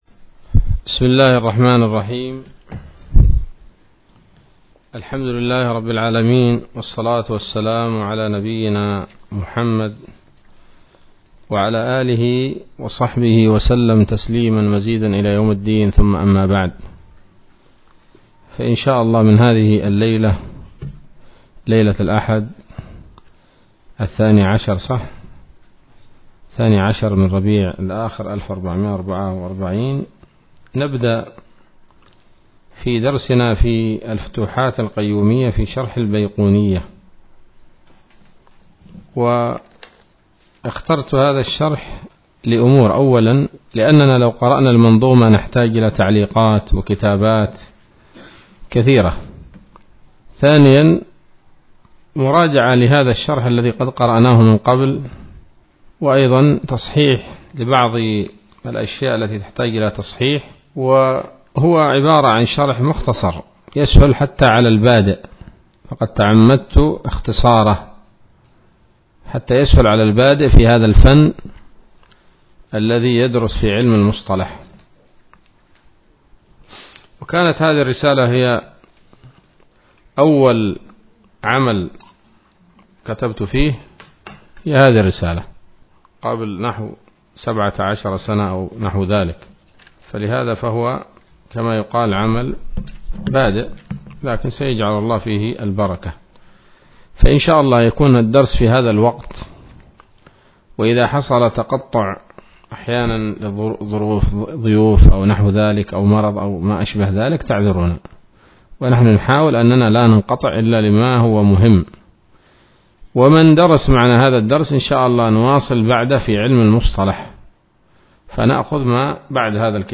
الدرس الأول من الفتوحات القيومية في شرح البيقونية [1444هـ]